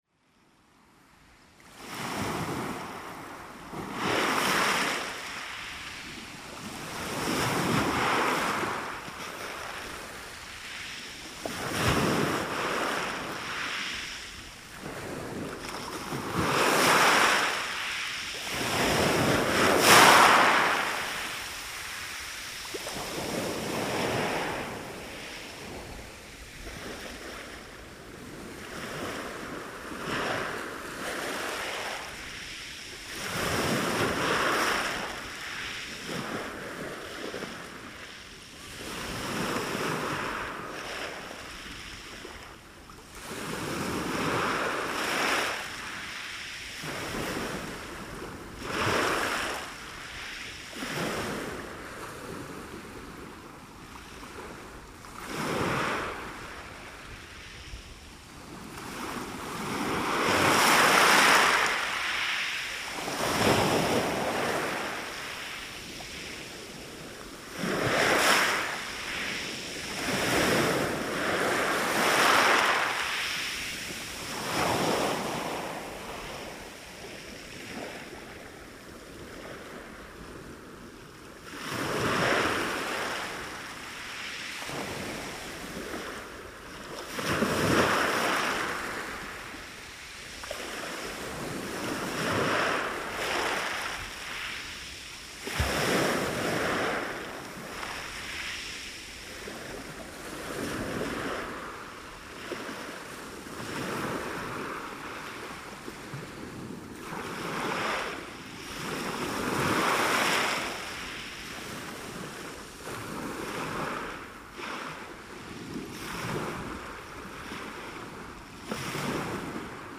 The sounds of summer at Bawdsey in the school holidays – a motorboat starts up and moves off, and then the passenger ferry arrives from Felixstowe Ferry